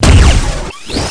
1 channel
RAILGUN.mp3